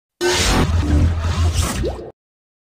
rick-and-morty-portal-gun-sound-effect_14096.mp3